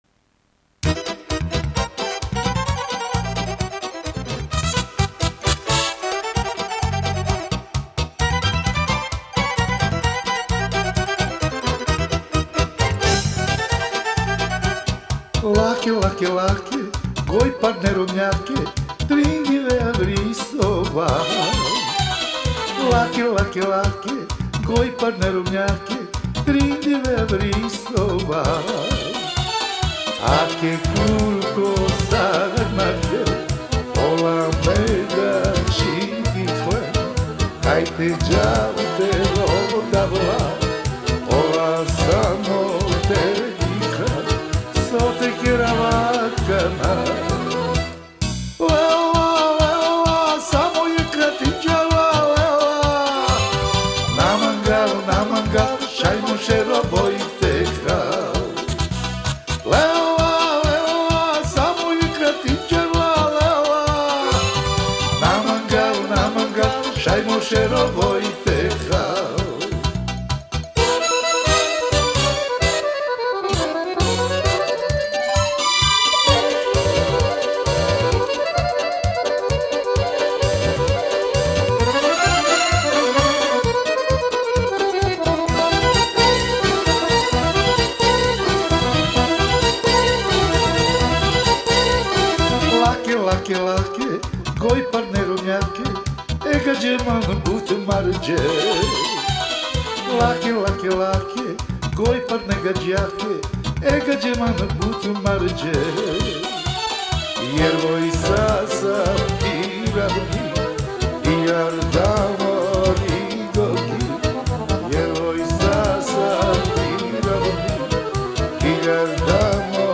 Genre: Gypsy Jazz, World, Ethnic, Folk Balkan